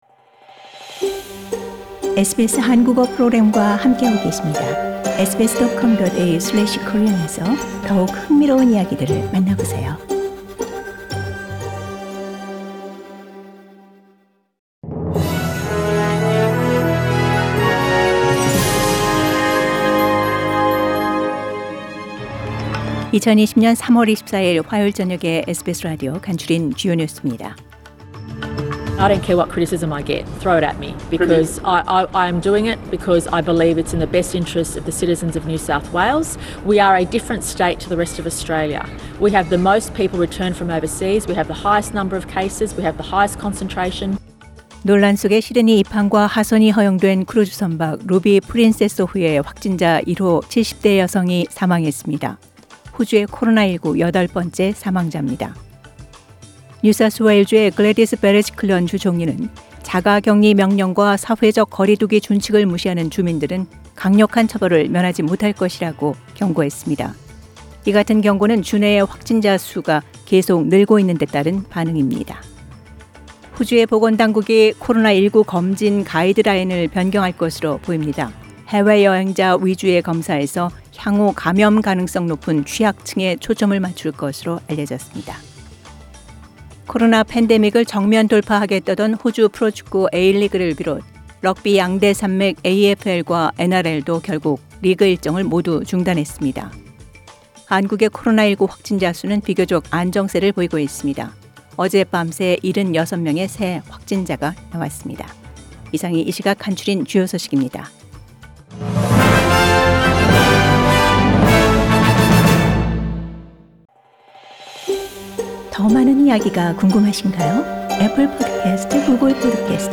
News headlines on 24 March, Tuesday from SBS Korean Program